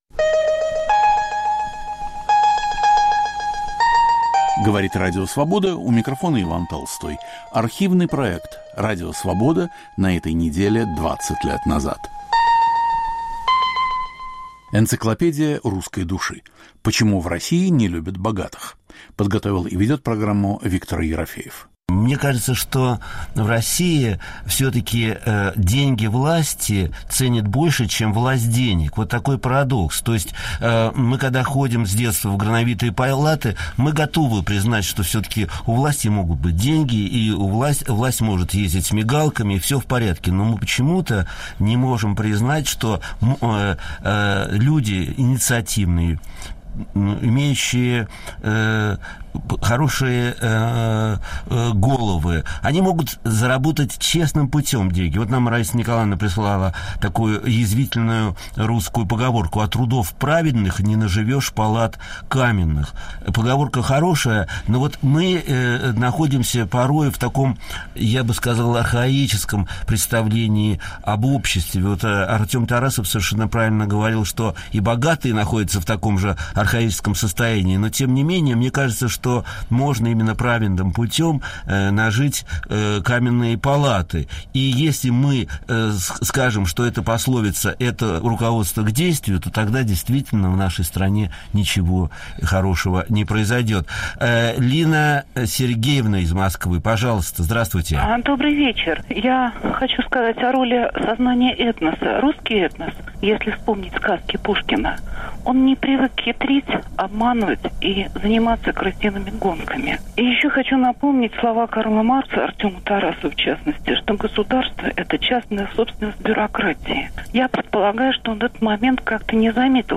Гости - поэт Евгений Рейн и бизнесмен Артем Тарасов. Автор и ведущий Виктор Ерофеев.